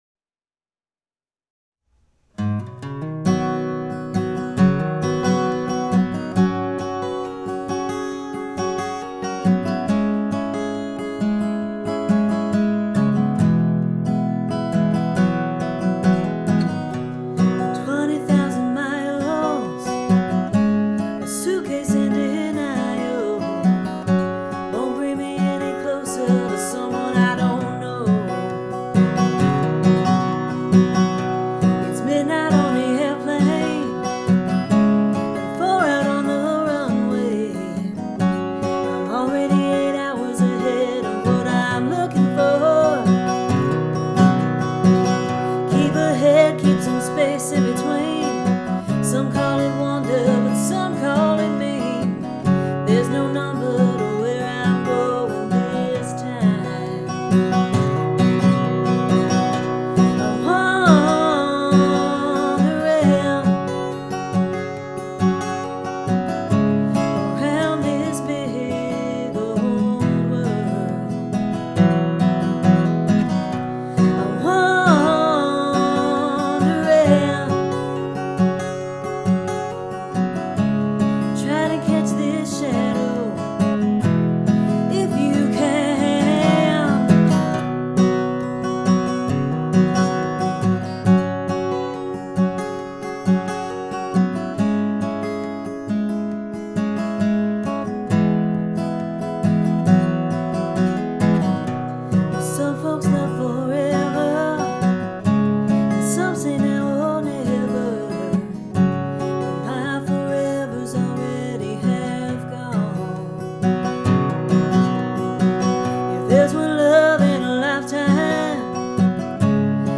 For your listening enjoyment: a new song I recorded while I was home for Thanksgiving.